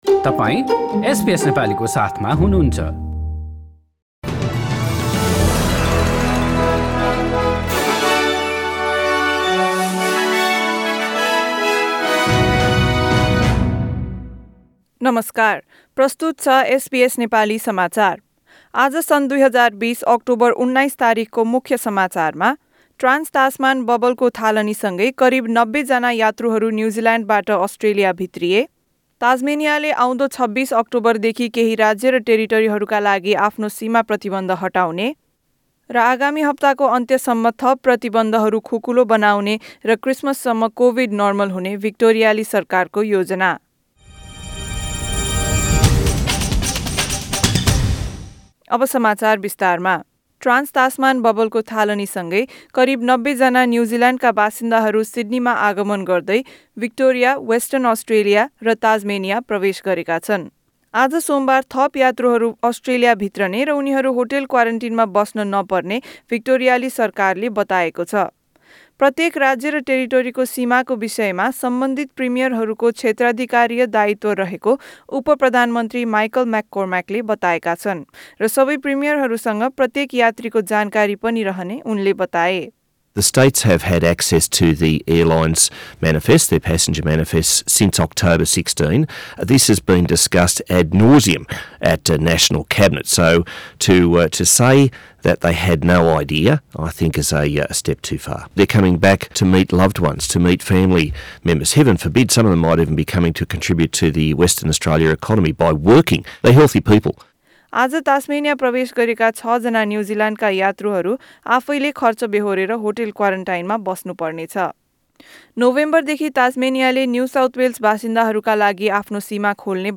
एसबीएस नेपाली अस्ट्रेलिया समाचार: सोमबार १९ अक्टोबर २०२०